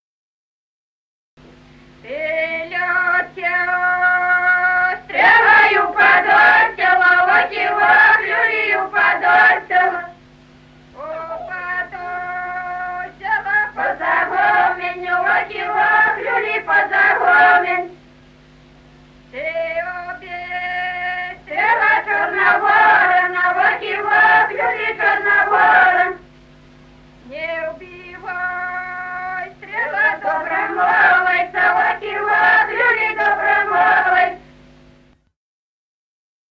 Народные песни Стародубского района «Ты лети, стрела», юрьевская таночная.
1959 г., с. Курковичи.